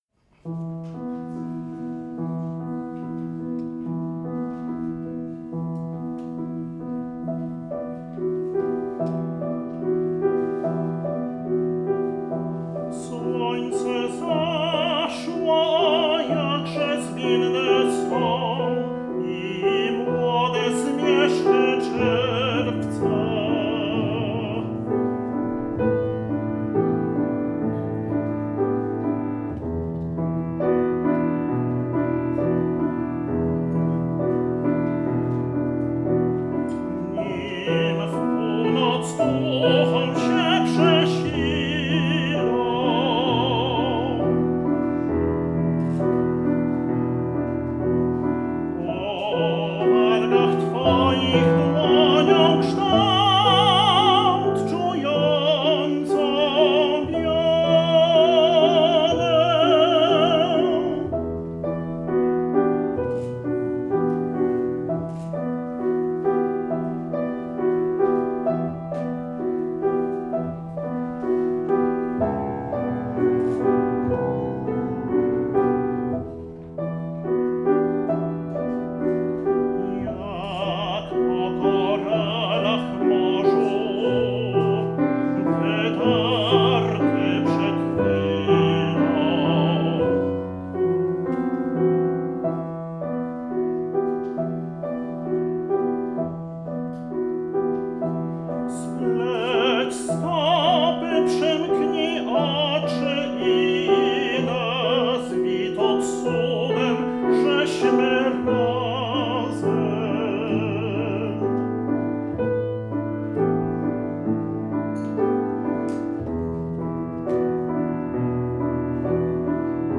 Lubię szeptać ci słowa, pieśni na głos solowy z fortepianem/ partia fortepianu